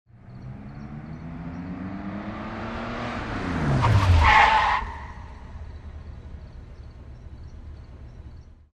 Звуки автомобиля
Автомобиль стремительно надвигается, внезапно останавливается